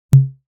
pong_sound.wav